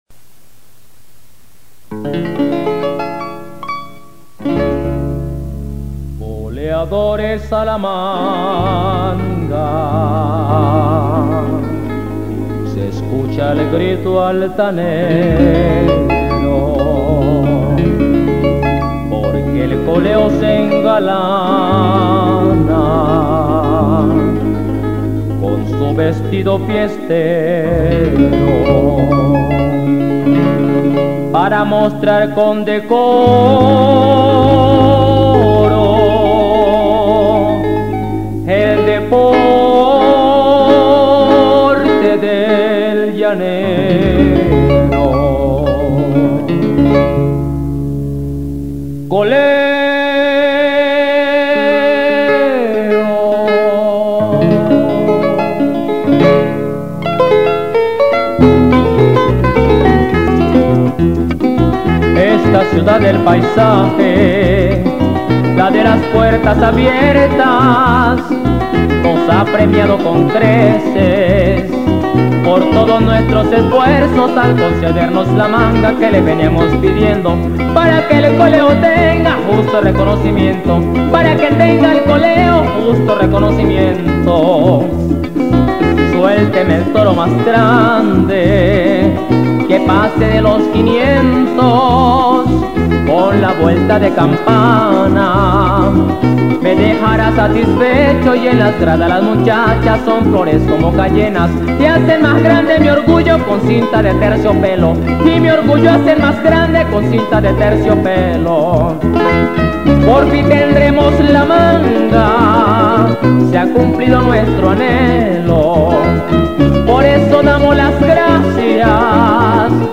Ritmo: Joropo – Entreverao.